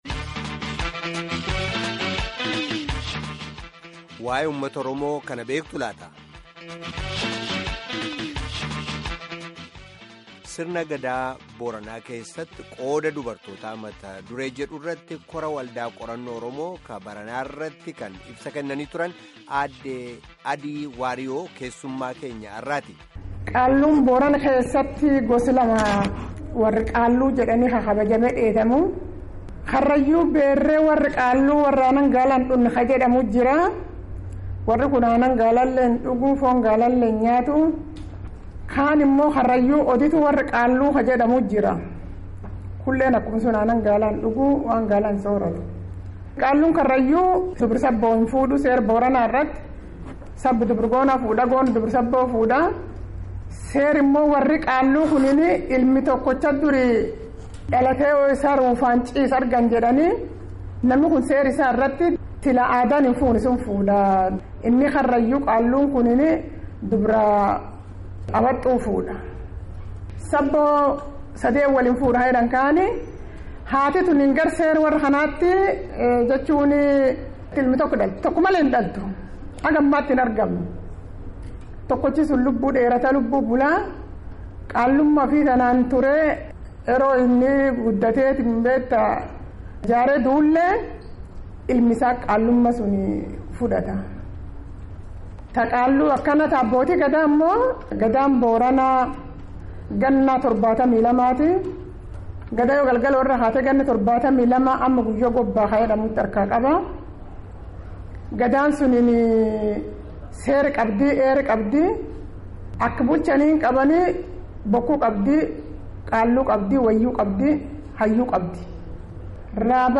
Gaaffii fi deebii geggeeffame caqasaa